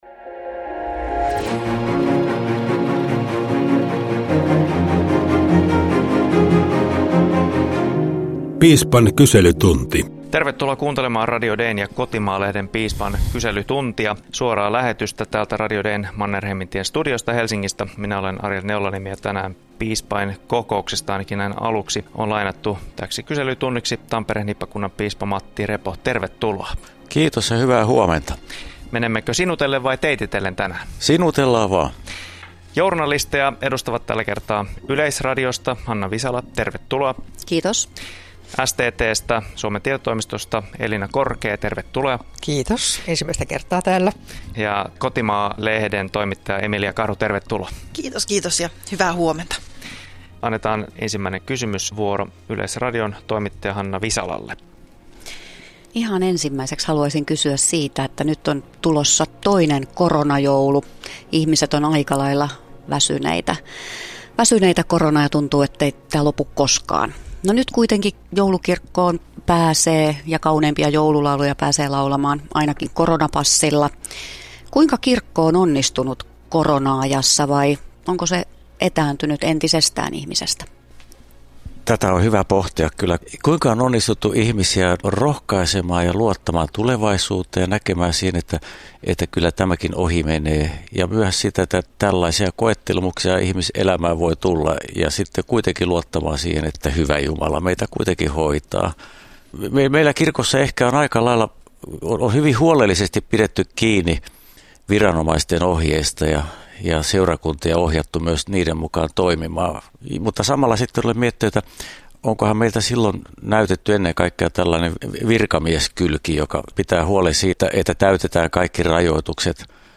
Tampereen hiippakuntaa vuodesta 2008 johtanut teologian tohtori Matti Repo asemoituu Radio Dein ja Kotimaa-lehden Piispan kyselytunti -lähetyksessä toimittajien eteen vastaamaan eri teemaisiin kysymyksiin.